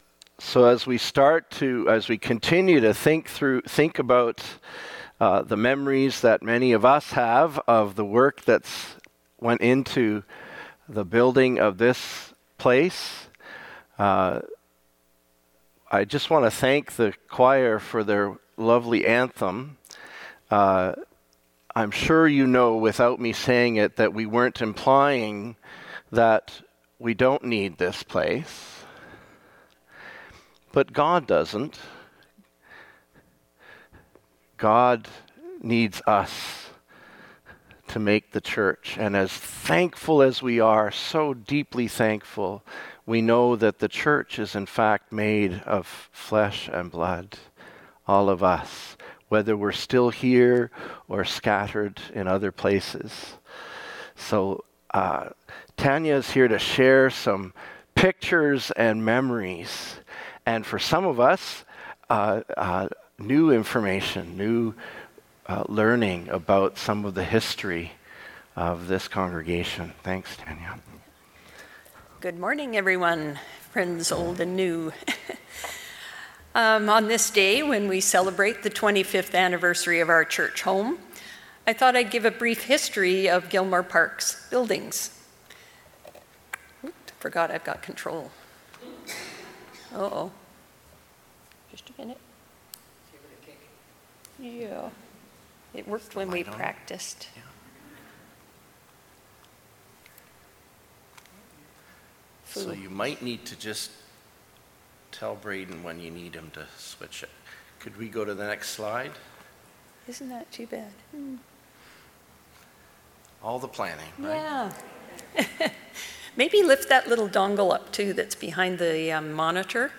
Sermons | Gilmore Park United Church
25th anniversary June 16th 2024